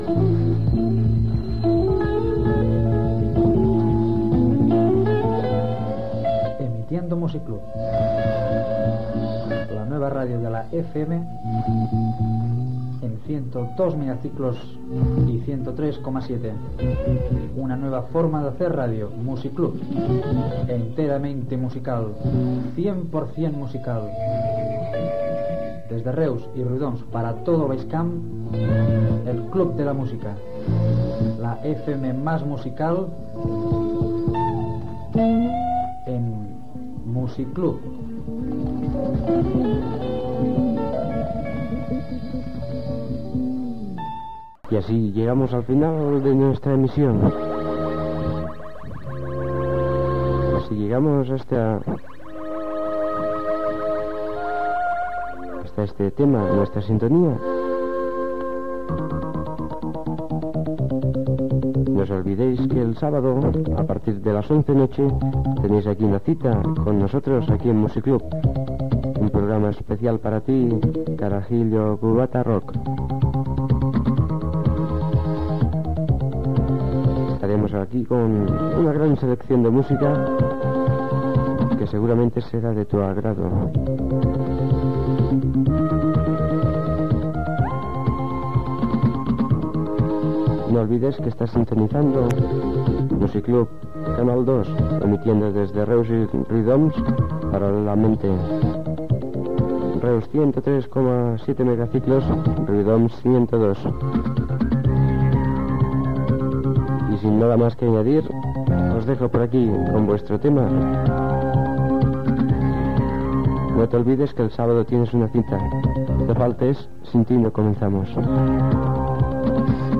36b2a0cabcd9b632a539c3faaf2687eb897d6656.mp3 Títol Music Club Emissora Music Club Titularitat Tercer sector Tercer sector Lliure Nom programa Cubata, carajillo, rock Descripció Final del programa. Gènere radiofònic Musical